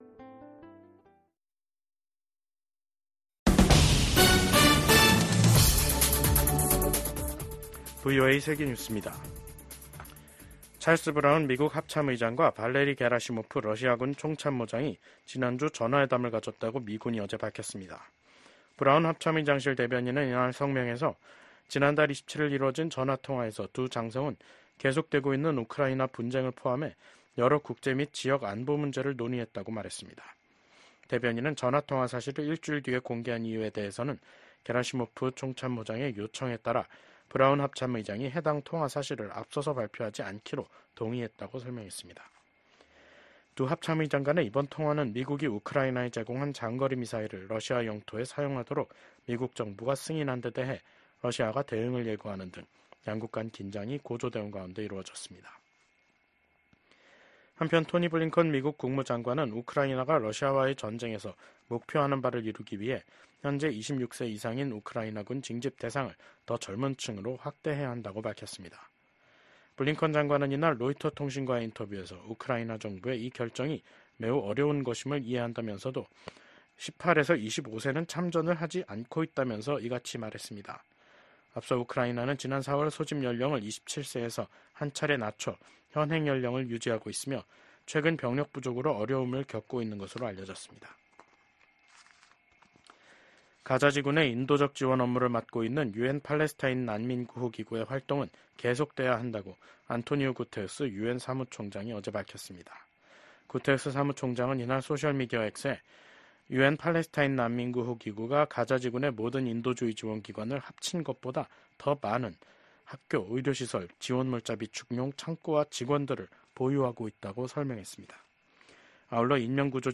VOA 한국어 간판 뉴스 프로그램 '뉴스 투데이', 2024년 12월 5일 3부 방송입니다. 한국 6개 야당이 비상계엄 선포와 관련해 발의한 윤석열 대통령 탄핵소추안이 7일 국회에서 표결에 부쳐질 예정입니다. 미국 국무장관이 한국은 전 세계에서 가장 모범적인 민주주의 국가 중 하나라면서 윤석열 한국 대통령의 비상계엄 해제 결정을 환영했습니다.